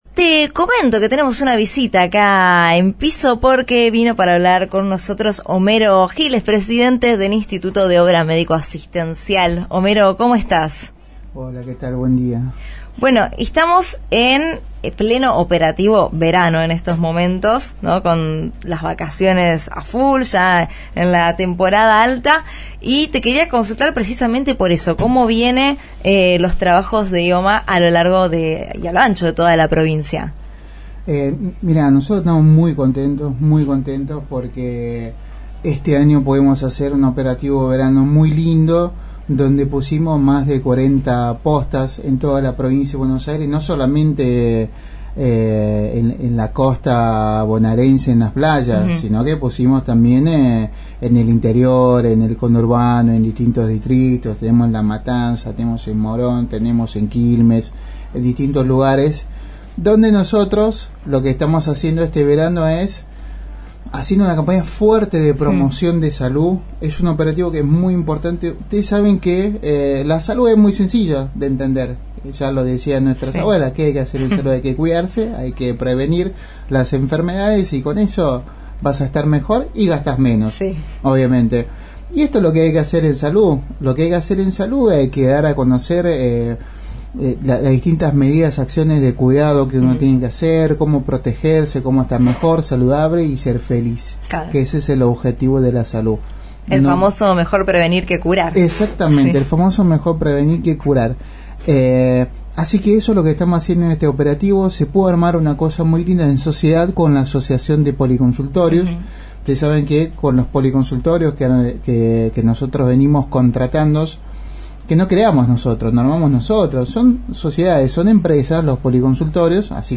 El titular del Instituto de Obra Médico Asistencial (IOMA), Homero Giles, fue entrevistado en el programa Código Baires, emitido por Radio La Plata 90.9.